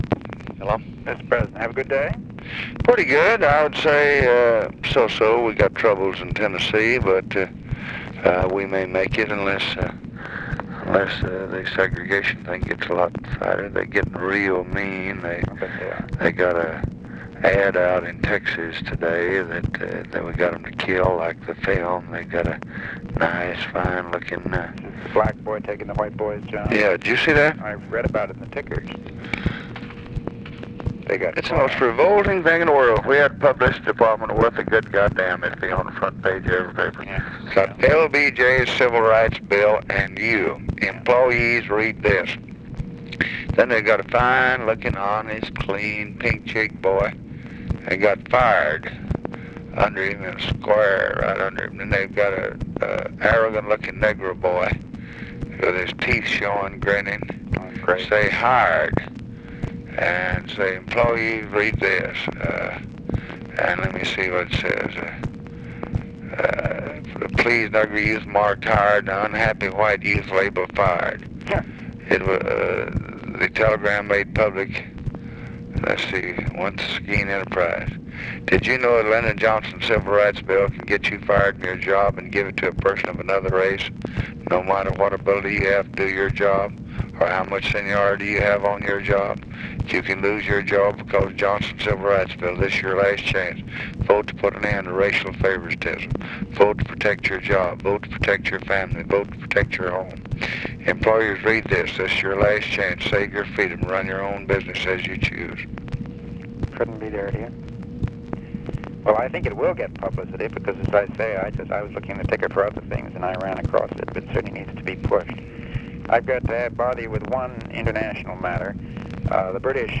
Conversation with MCGEORGE BUNDY, October 24, 1964
Secret White House Tapes